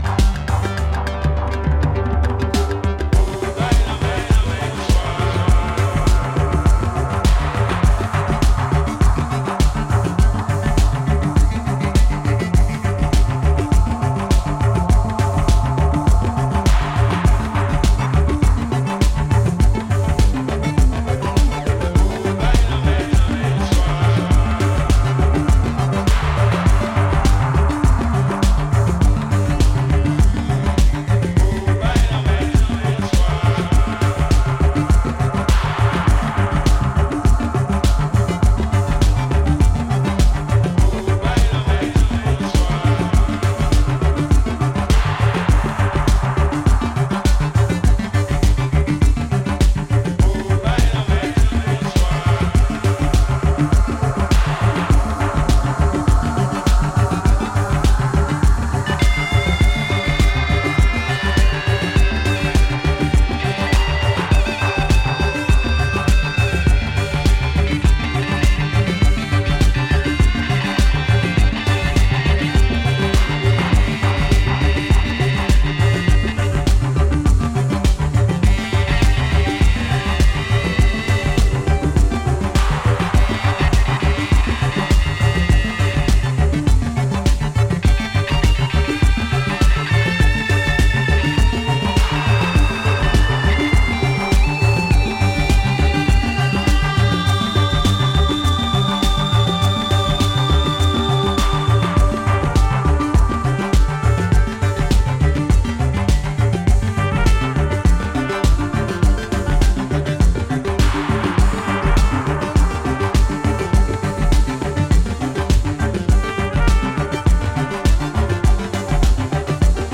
give the track a more Afro cosmic and dance-floor appeal.